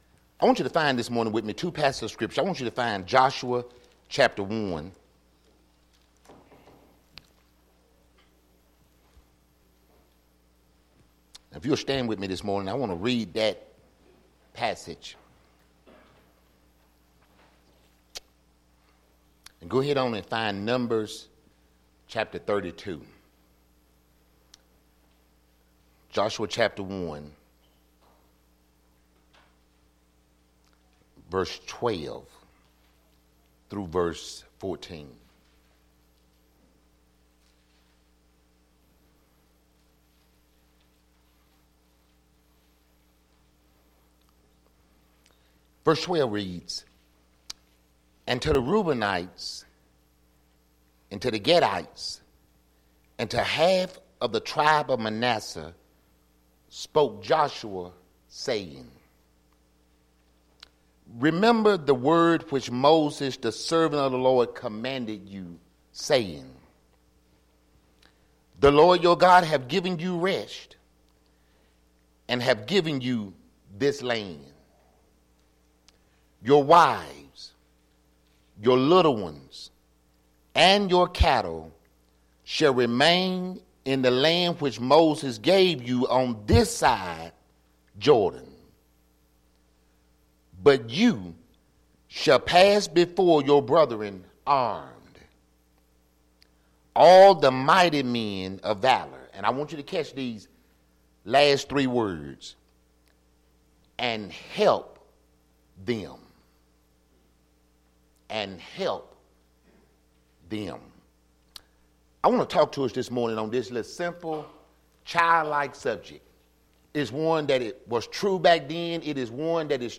Solid Rock Baptist Church Sermons